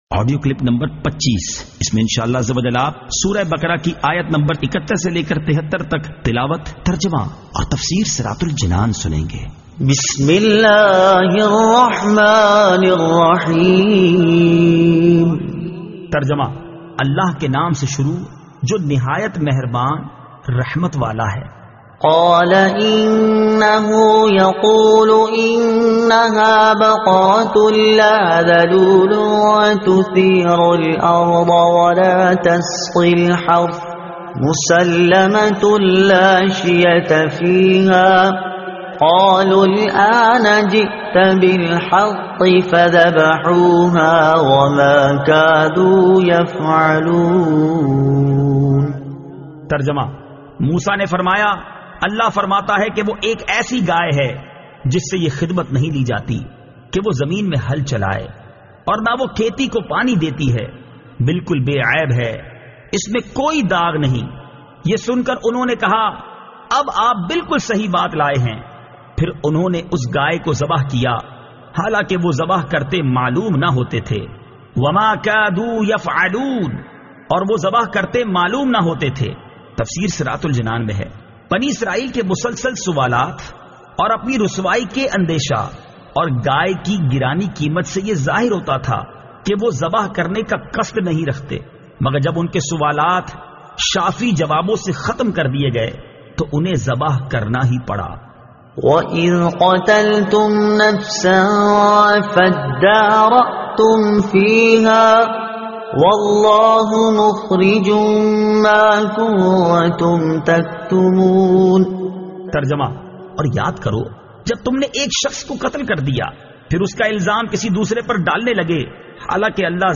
Surah Al-Baqara Ayat 71 To 73 Tilawat , Tarjuma , Tafseer